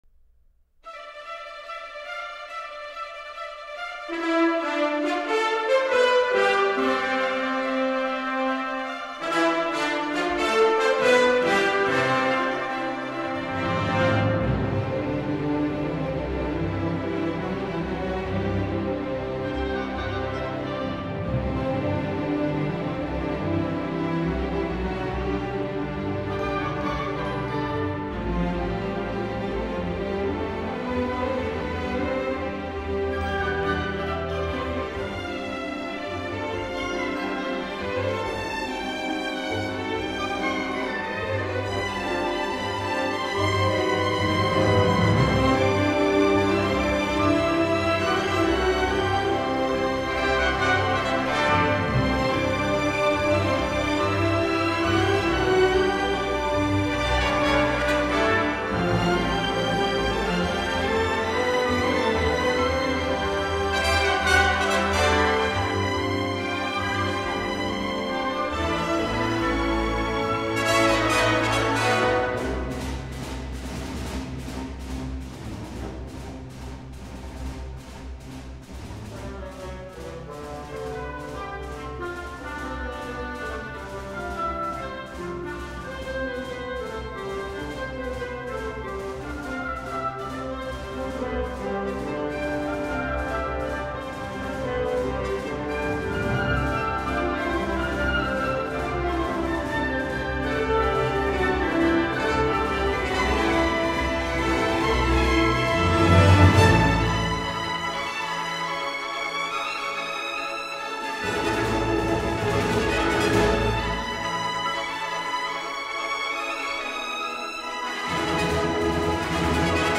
stately, dignified and beautifully melodic composition